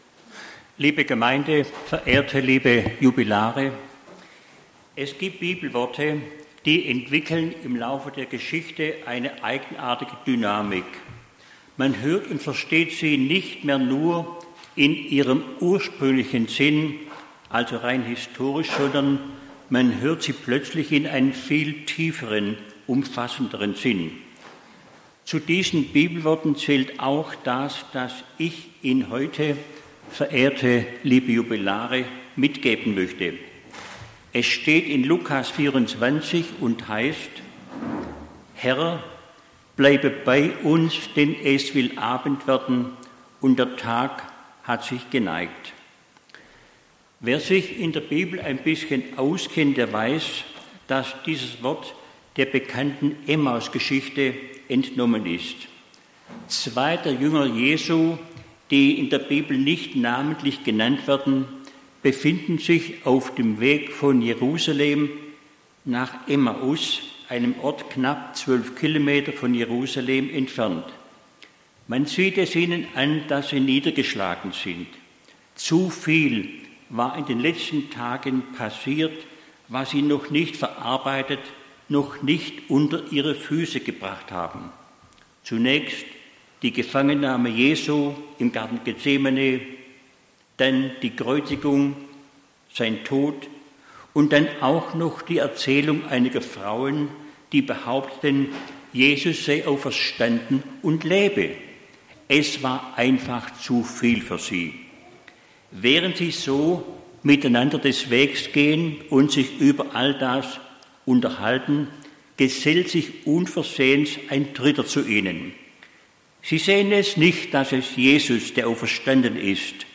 Predigt zur Goldenen Konfirmation in Bernloch am 05.